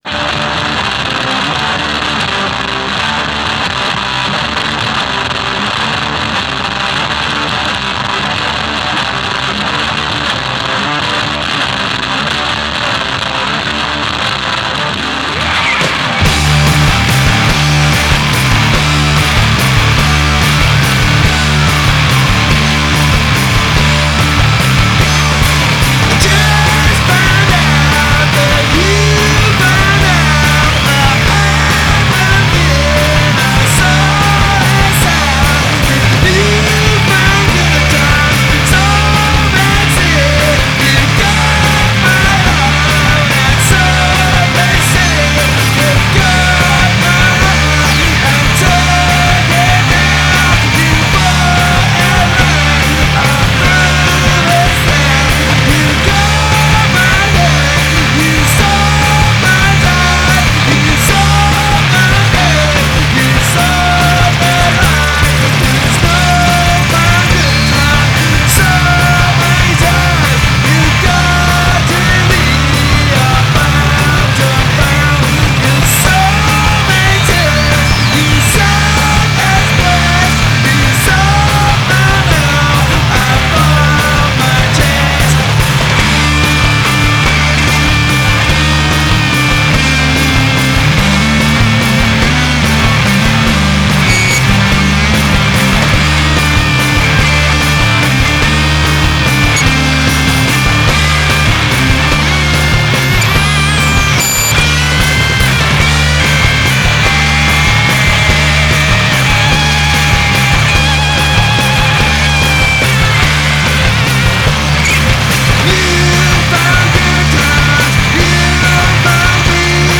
"Grand Masters of the neanderthal riff." (Uncut)